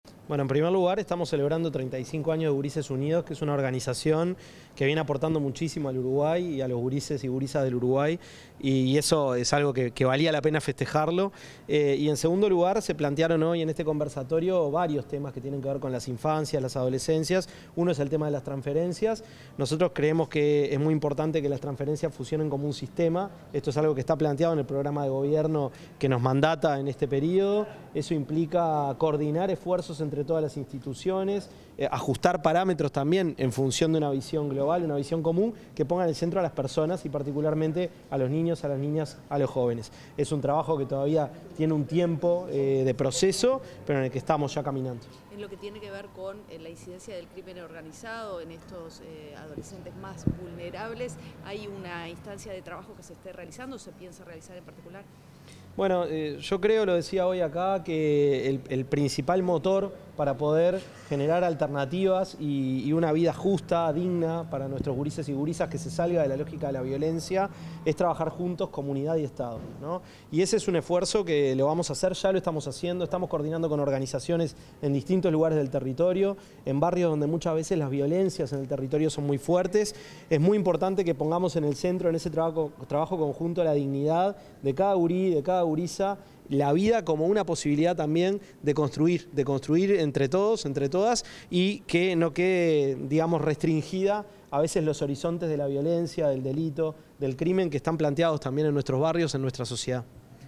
El ministro de Desarrollo Social, Gonzalo Civila, realizó declaraciones a la prensa tras participar en el conversatorio Infancias, Adolescencias y